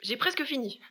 VO_ALL_Interjection_08.ogg